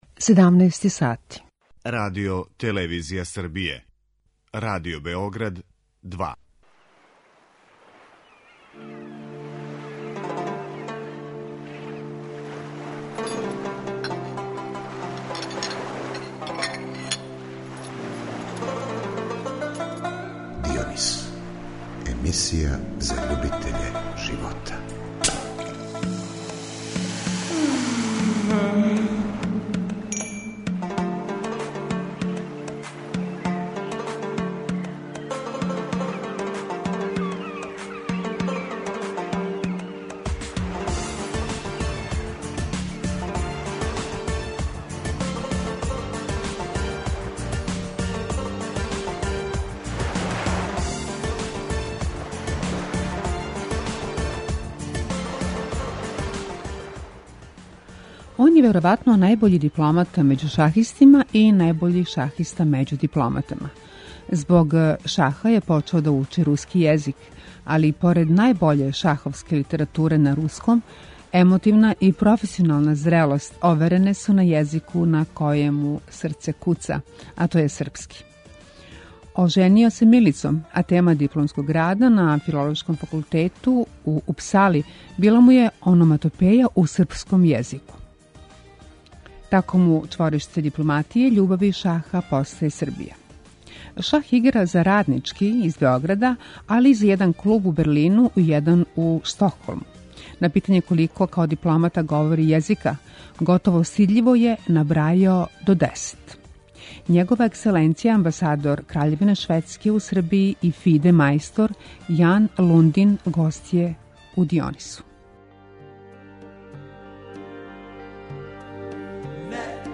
Његова екселенција амбасадор Краљевине Шведске у Србији и ФИДЕ мајстор Јан Лундин гост је у Дионису .